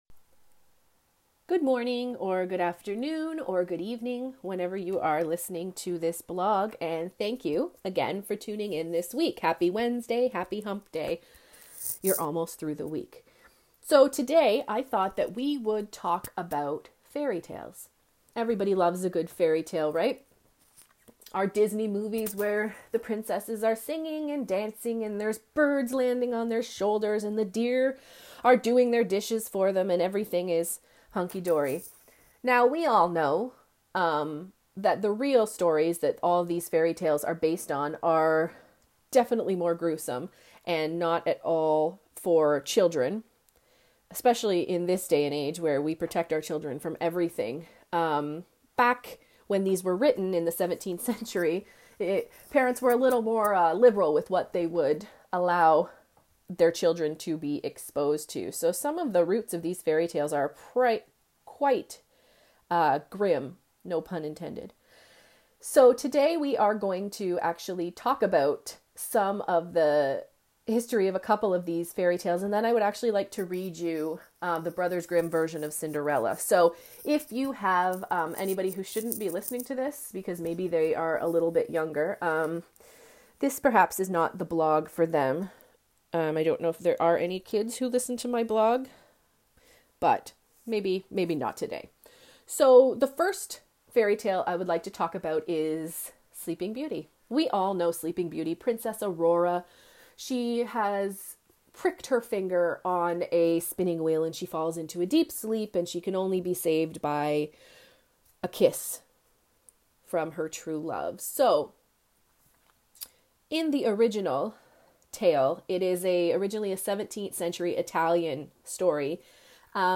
Also, included in this blog is a reading of the original Brother's Grim "Cinderella".